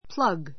plʌ́ɡ プ ら グ